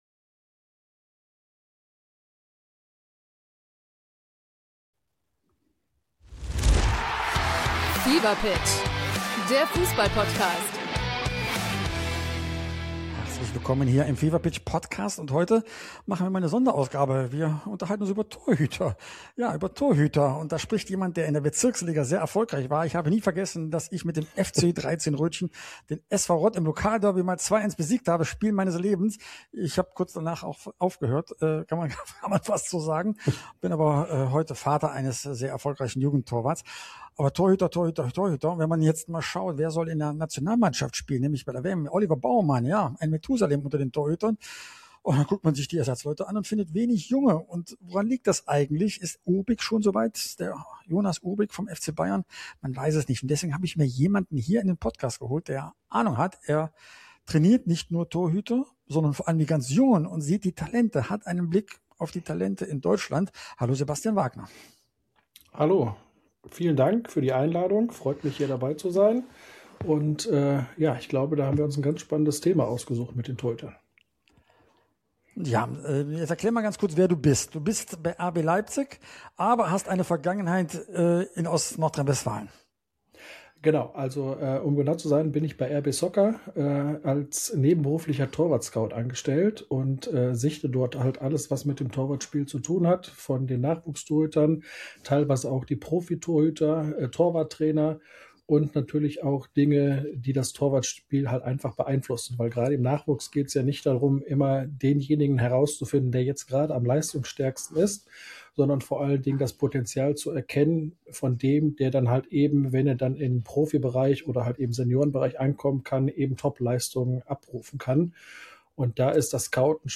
Das große Sammer-Interview, Teil 1